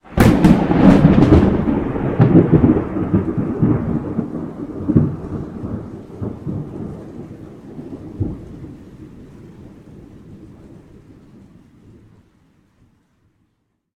Divergent/thunder_19.ogg at ca37fcf28b42bd5c71e4f8d9fb8ca423709ffcf4
thunder_19.ogg